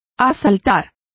Complete with pronunciation of the translation of invade.